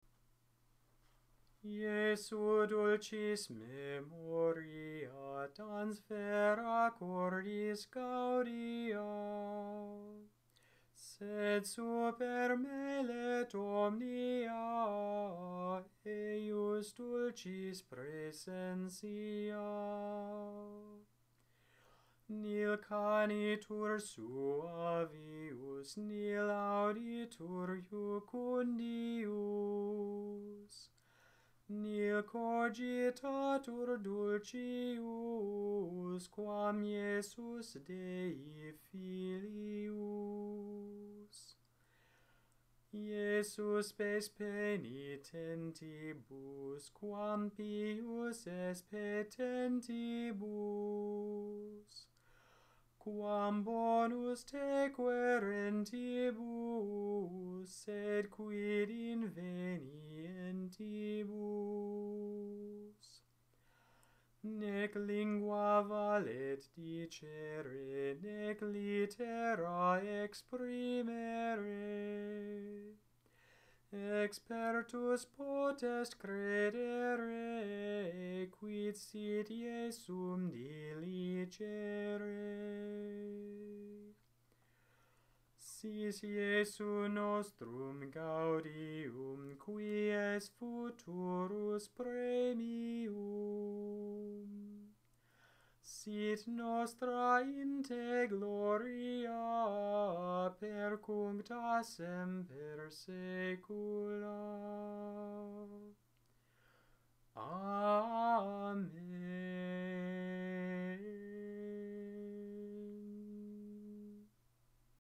Gregorian, Catholic Chant Jesu, Dulcis Memoria